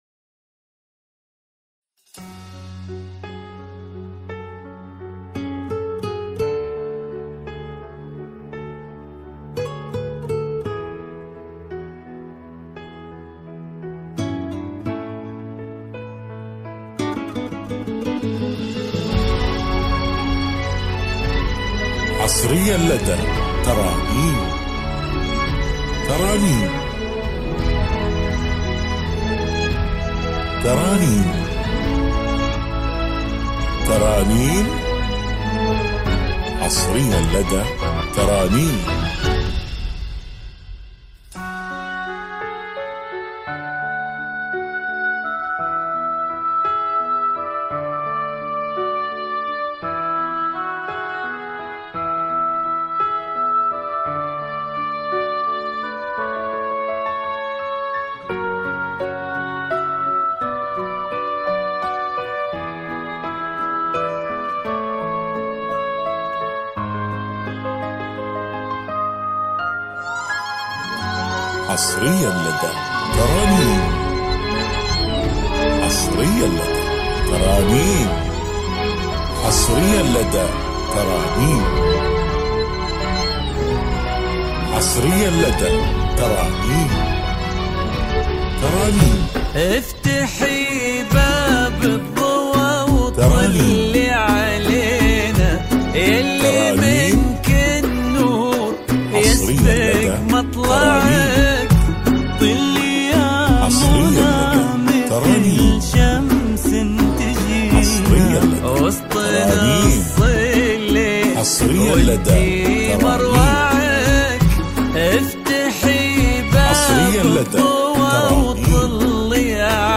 زفات مع الاسماء حسب الطلب